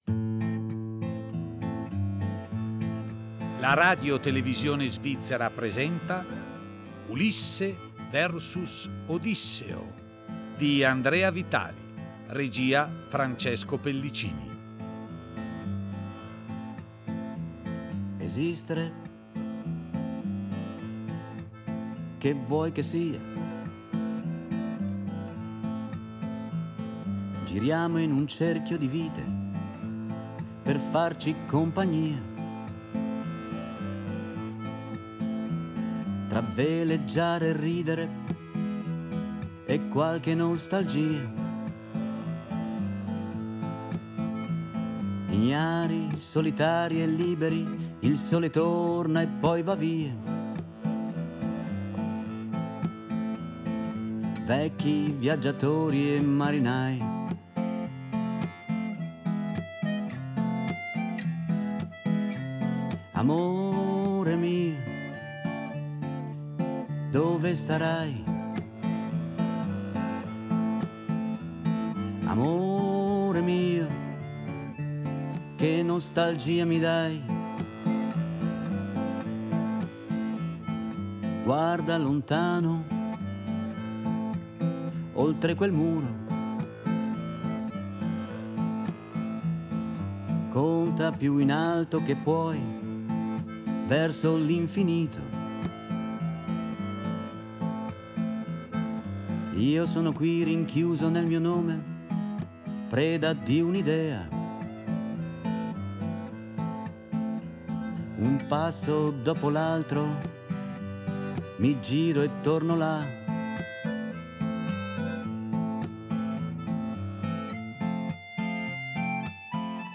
fisarmonica
chitarra